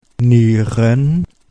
Ääntäminen
Ääntäminen Tuntematon aksentti: IPA: /ˈniː.rən/ Haettu sana löytyi näillä lähdekielillä: saksa Käännöksiä ei löytynyt valitulle kohdekielelle. Nieren on sanan Niere monikko.